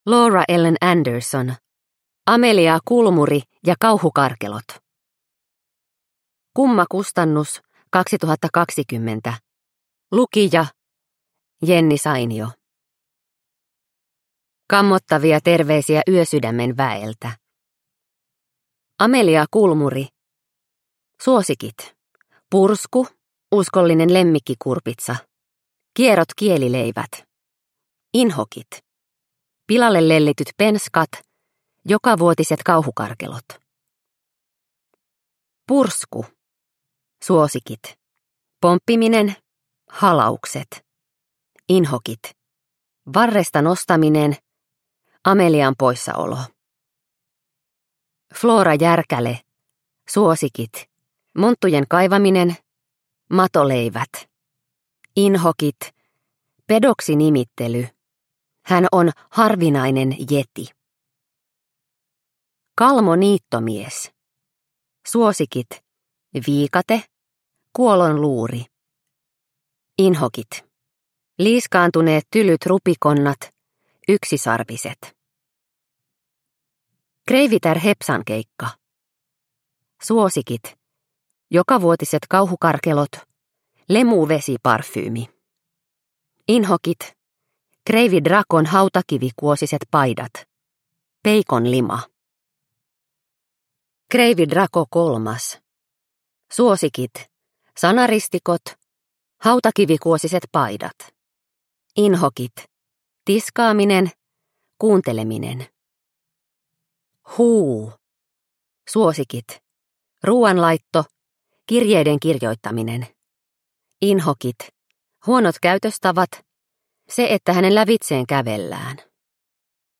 Amelia Kulmuri ja kauhukarkelot – Ljudbok – Laddas ner